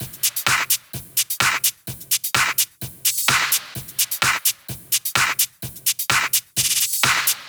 VFH3 Mini Kits Drums